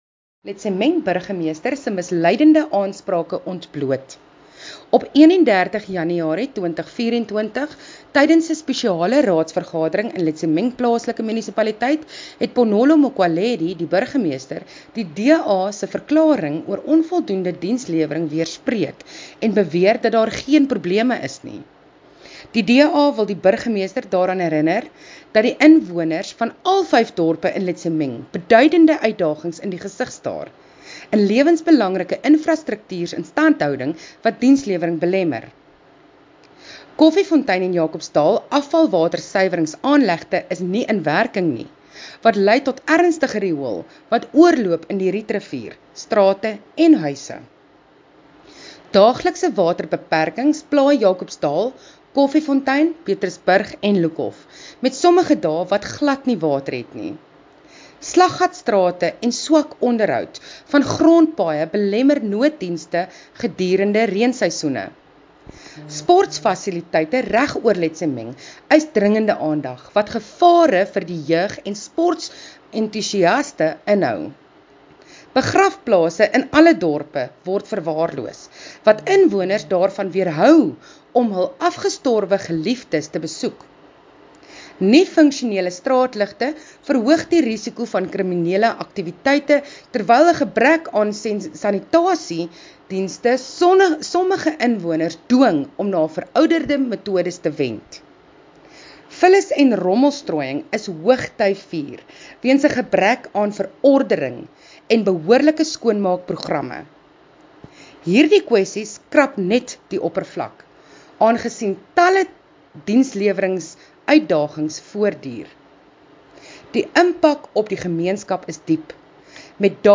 Afrikaans soundbites by Cllr Mariska Potgieter and